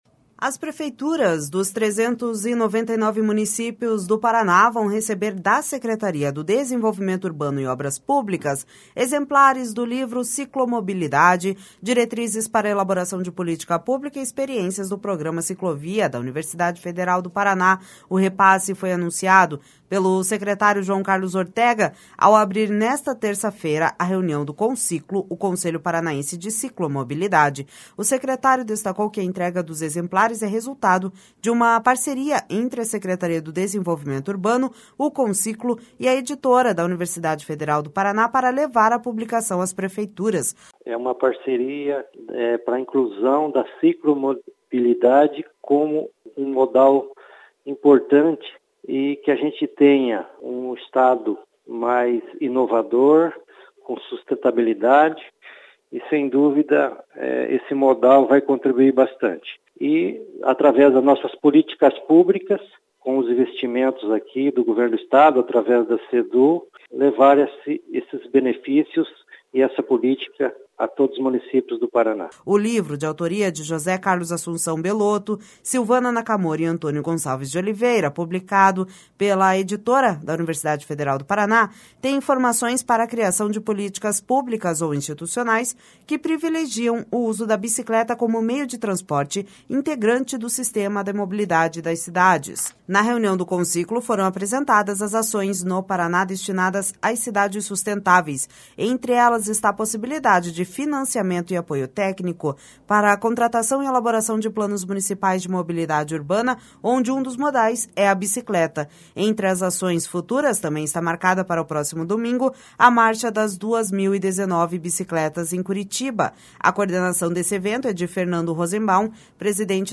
O repasse foi anunciado pelo secretário João Carlos Ortega ao abrir, nesta terça-feira, a reunião do Conciclo, o Conselho Paranaense de Ciclomobilidade. O secretário destacou que a entrega dos exemplares é resultado de uma parceria entre a Secretaria do Desenvolvimento Urbano, o Conciclo e a Editora da Universidade Federal do Paraná para levar a publicação às prefeituras.// SONORA JOÃO CARLOS ORTEGA//